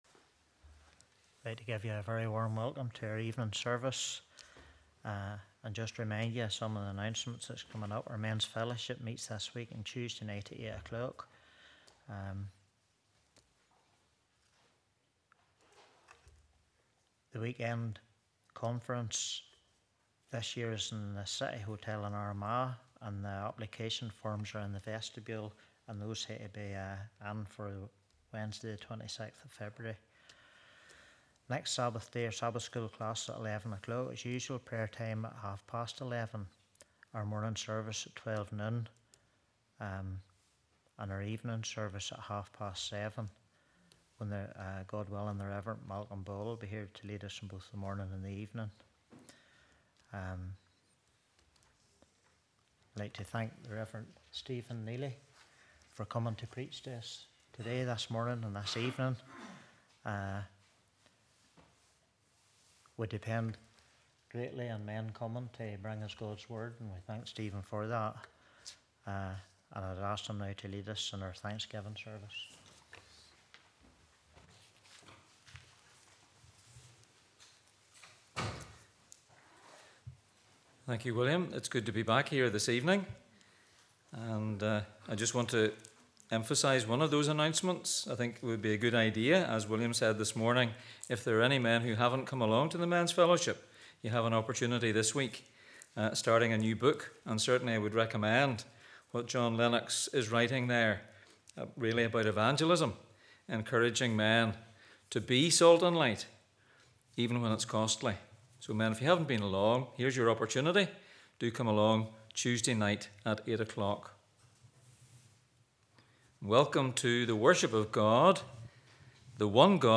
Thanksgiving Service 19/01/25